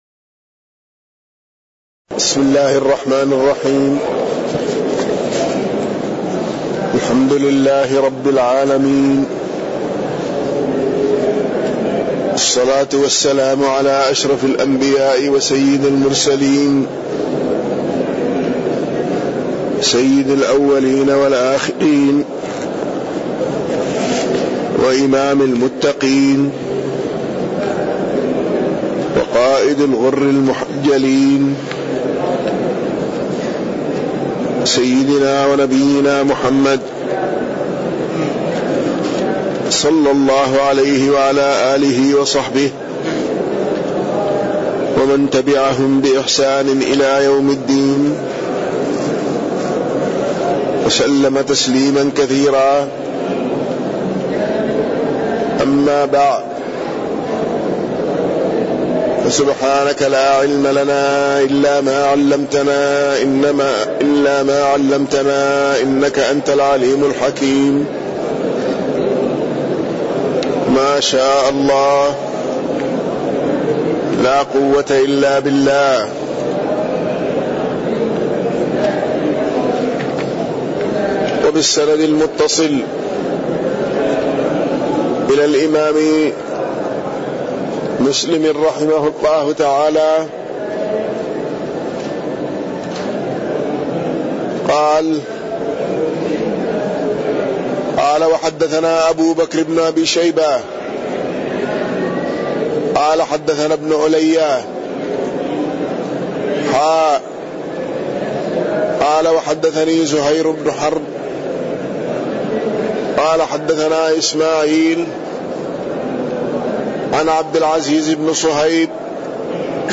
تاريخ النشر ٢١ ربيع الأول ١٤٣١ هـ المكان: المسجد النبوي الشيخ